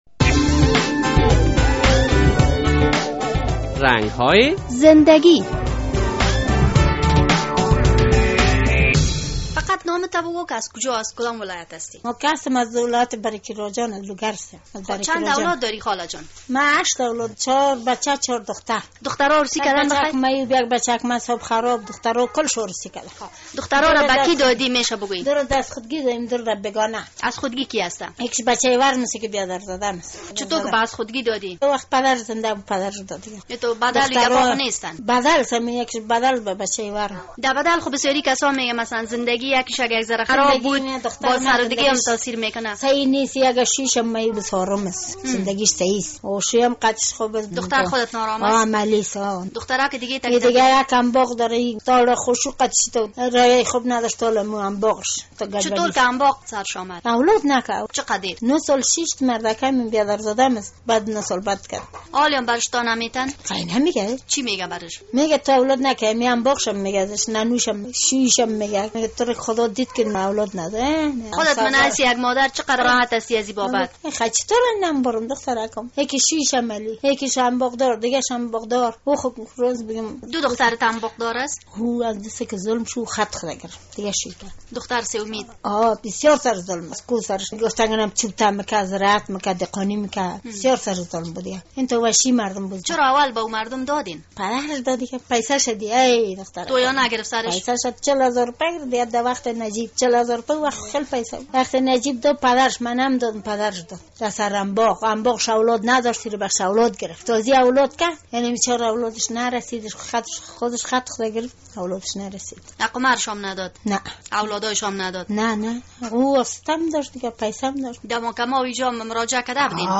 در این برنامهء رنگ های زندگی با یک خانمی صحبت شده است. این خانم از لوگر است و می گوید که تمام دختر هایش عروسی کرده و اکثر شان نا آرام هستند...